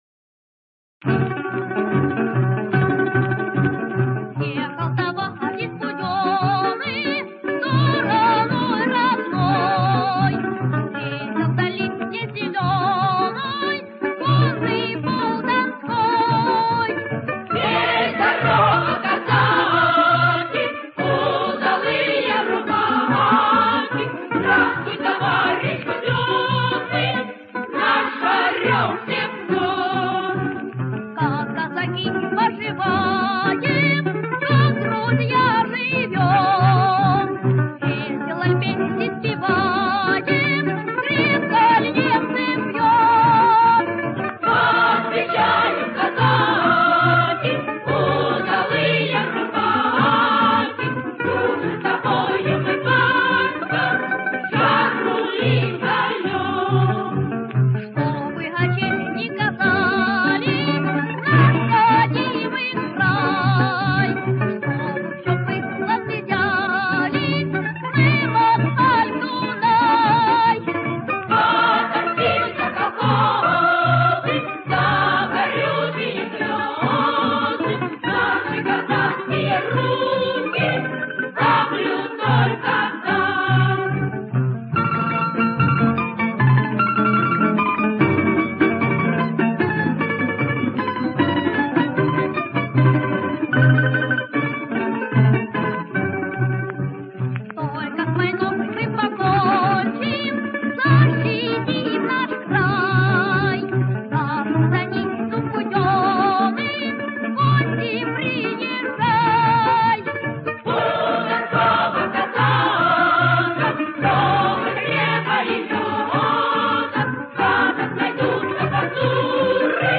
Неумеренно веселая донская песня военных лет